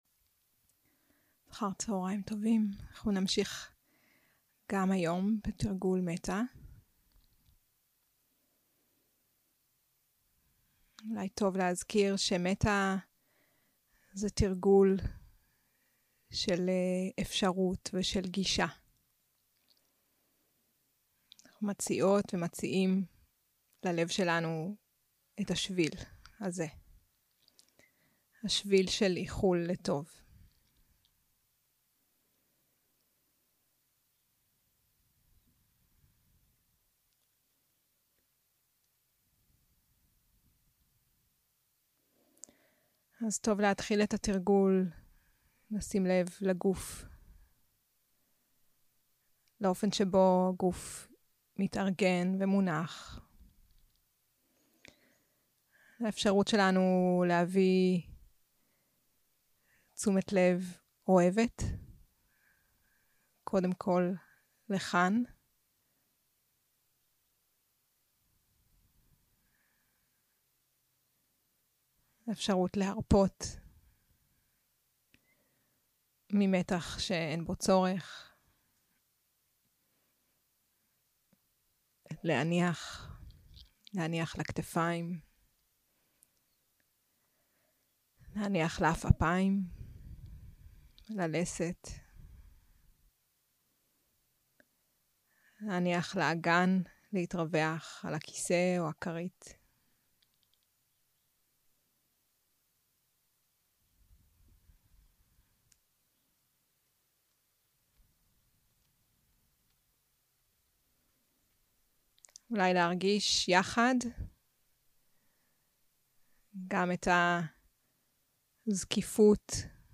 יום 5 - הקלטה 12 - צהרים - מדיטציה מונחית - מטא והכרת תודה 0:00 0:00 סוג ההקלטה: Dharma type: Guided meditation שפת ההקלטה: Dharma talk language: Hebrew